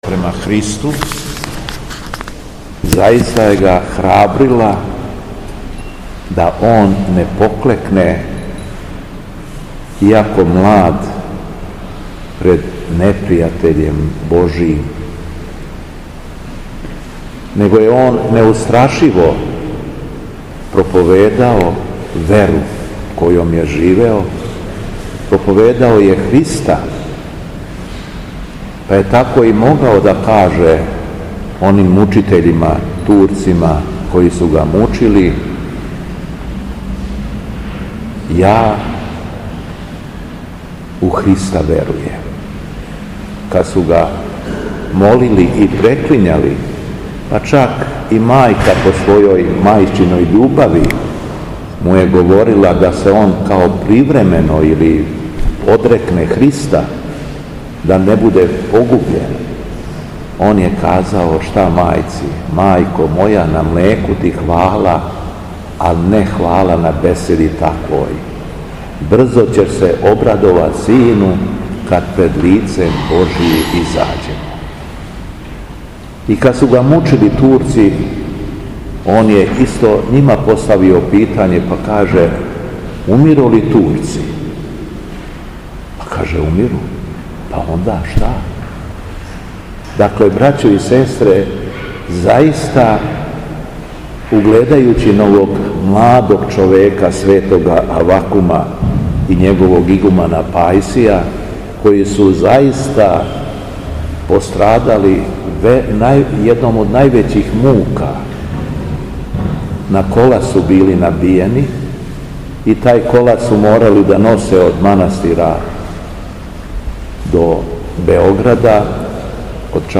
У понедељак двадесет и осми по Духовима, када наша света Црква прославља светог мученика Пајсија и ђакона Авакума, Његово Високопреосвештенство Митрополит шумадијски Господин Јован, служио је свету архијерејску литургију у храму Светога Саве у крагујевачком насељу Аеродром.
Беседа Његовог Високопреосвештенства Митрополита шумадијског г. Јована
По прочитаном Јеванђељу по Матеју, Његово Високопреосвештенство обратио се верном народу поучном беседом: